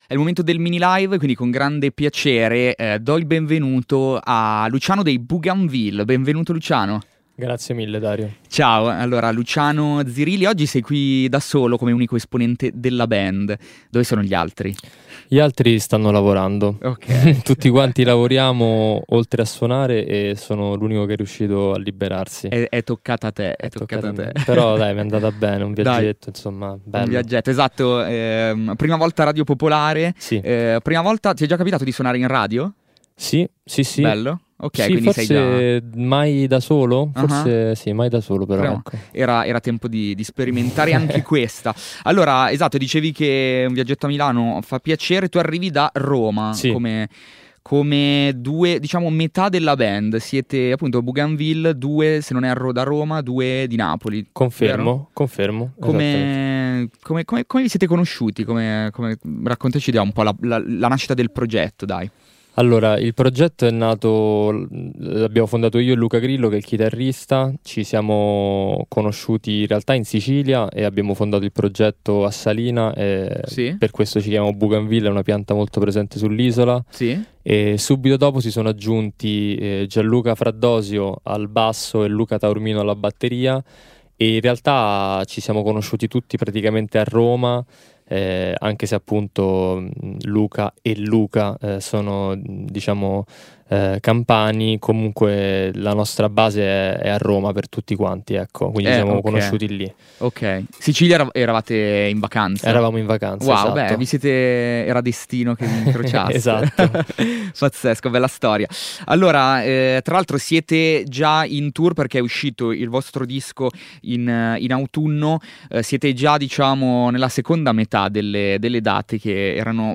L’album, frutto di sessioni di scrittura fortemente collettive, mette insieme i differenti background di ogni musicista e tra psichedelìa, funk, elettronica e b-movies anni '70, parla di com'è avere trent’anni al giorno d'oggi.